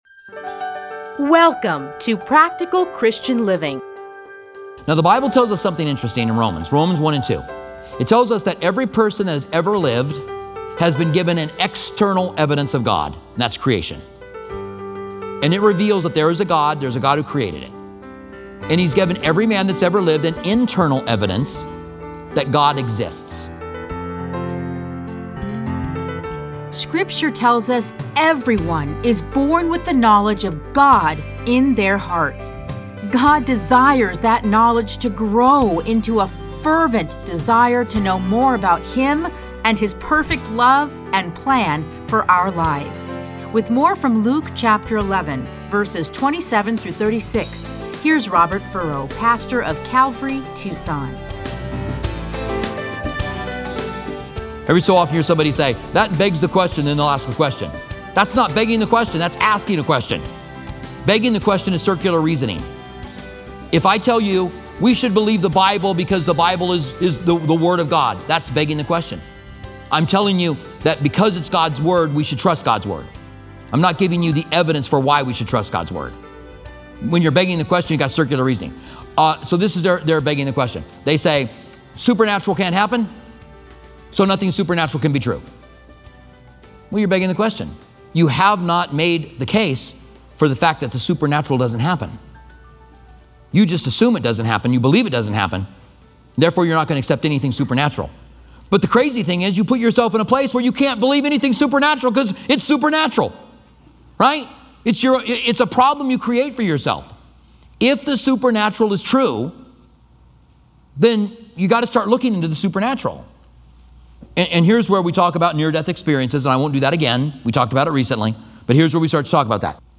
Listen to a teaching from Luke Luke 11:27-36.